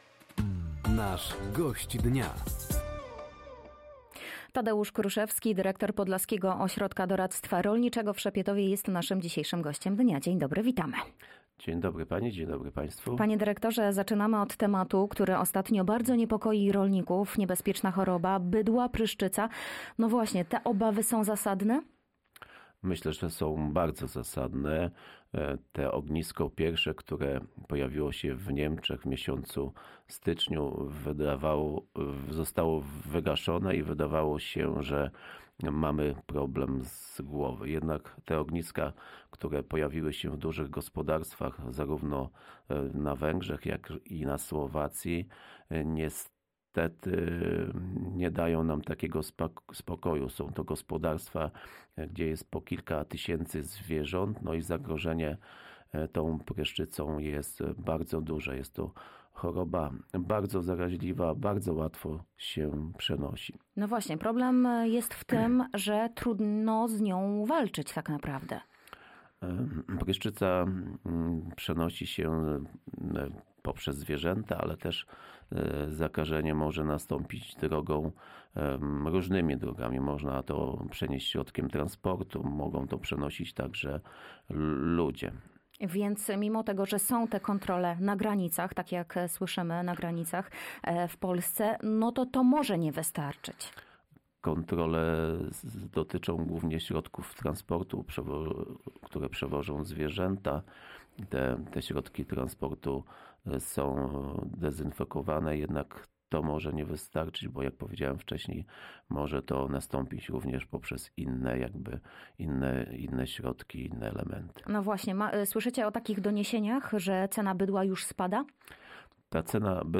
Zagrożenie niebezpieczną chorobą bydła – pryszczycą, susza, zmiany w kampanii dopłat bezpośrednich, a także ,,Wiosenne Targi Ogrodnicze” – tych tematów nie zabrakło podczas rozmowy z Gościem Dnia Radia Nadzieja.